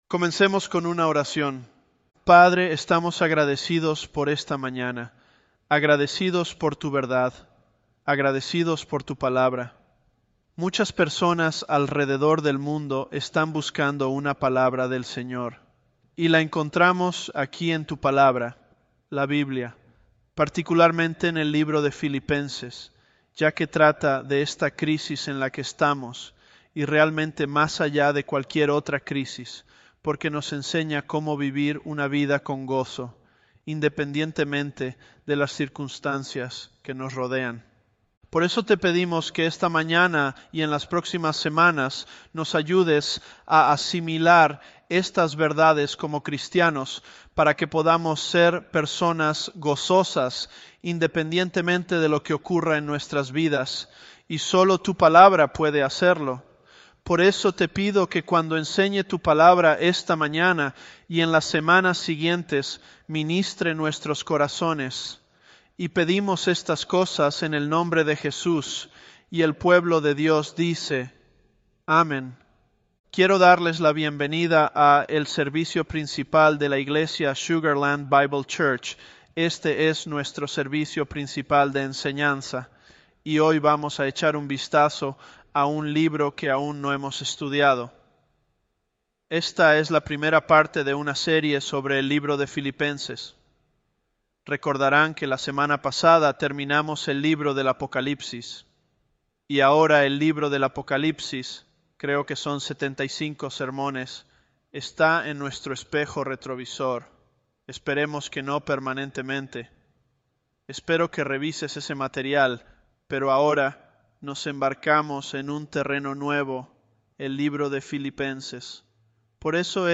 Elevenlabs_Philippians001.mp3